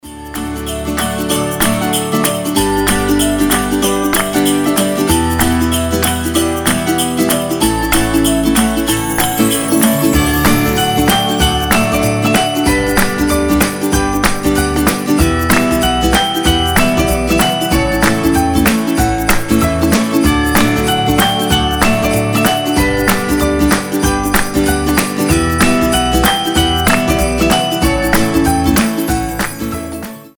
мелодичные
без слов
добрые
праздничные
колокольчики
рождественские